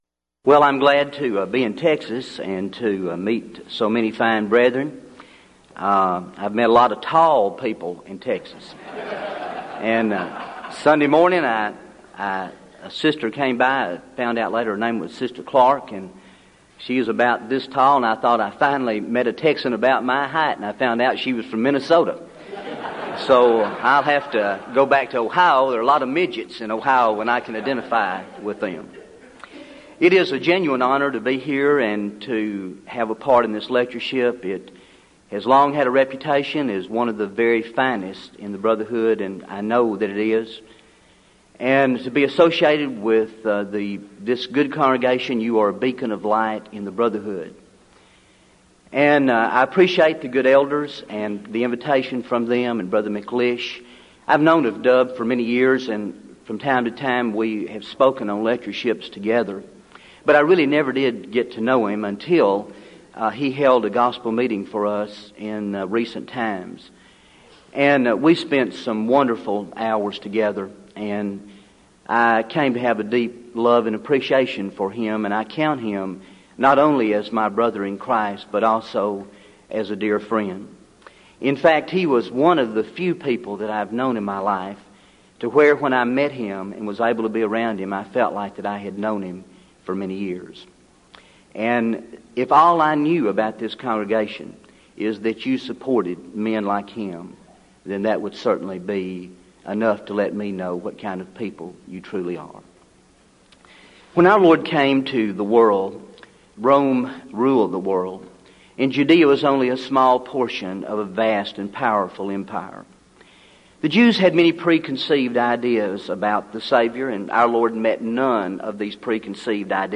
Event: 1998 Denton Lectures
lecture